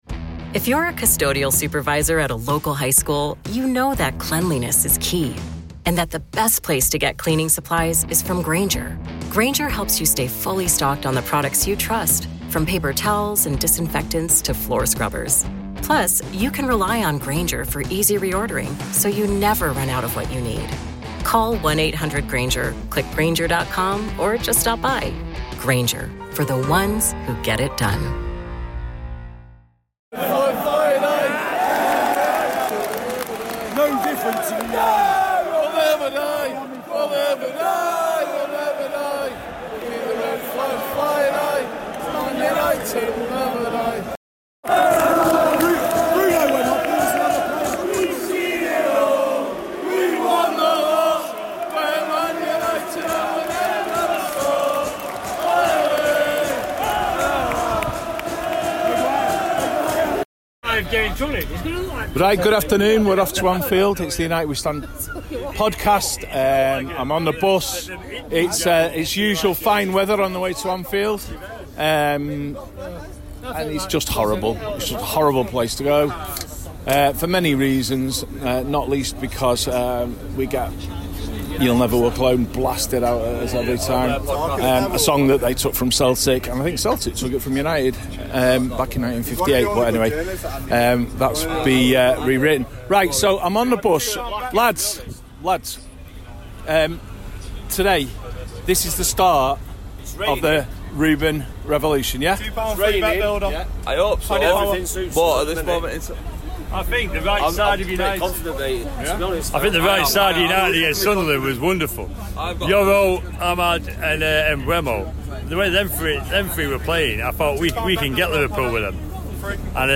A short podcast from the away end at Anfield.